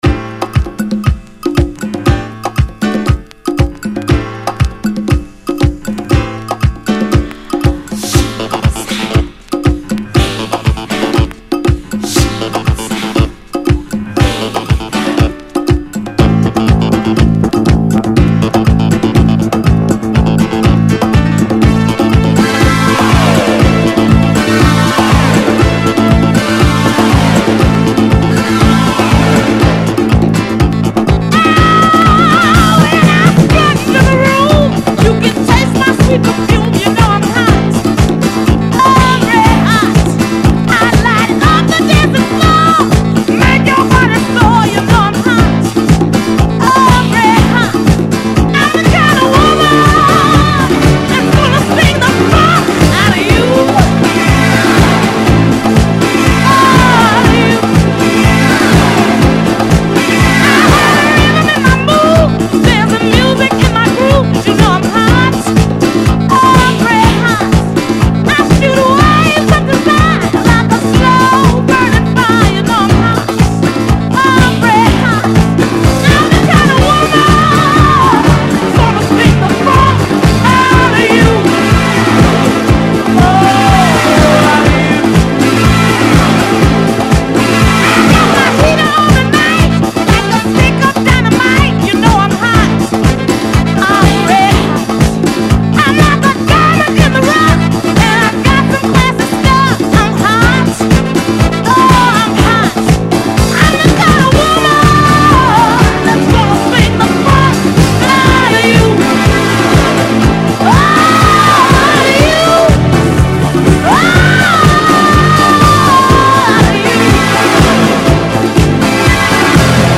Funky Disco